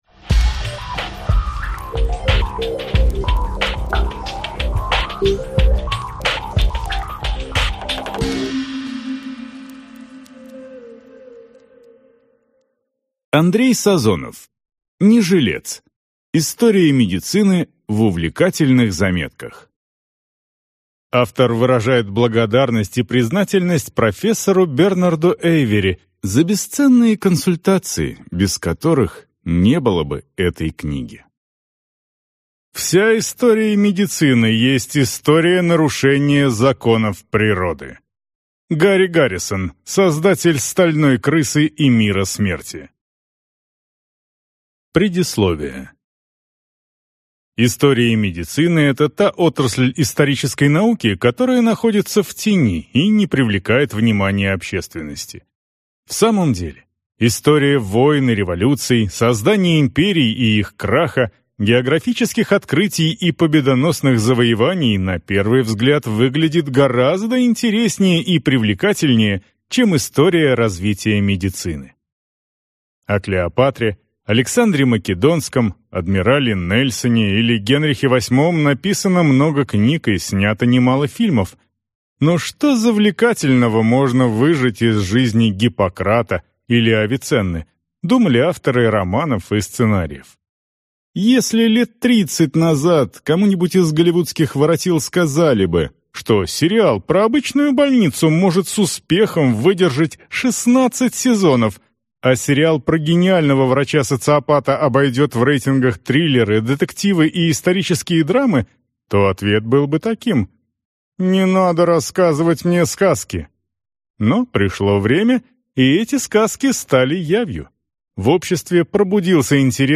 Аудиокнига Не жилец!